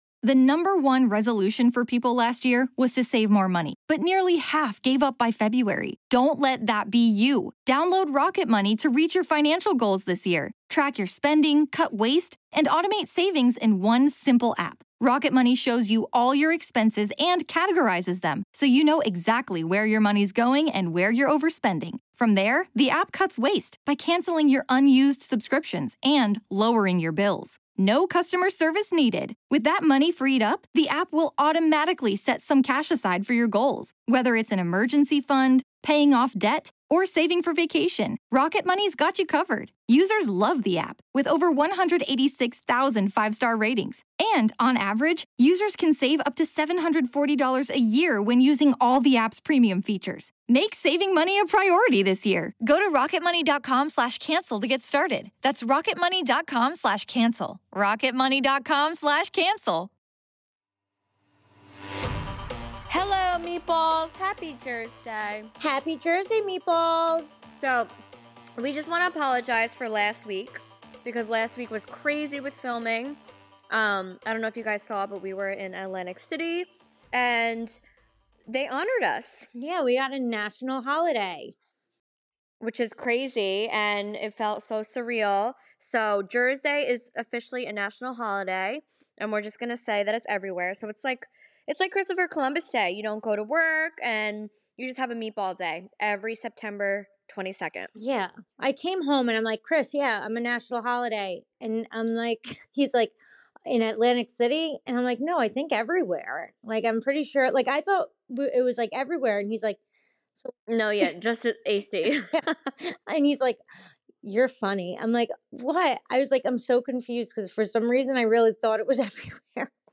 This episode, Deena and Snooki take some more fun fan questions!